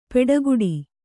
♪ peḍaguḍi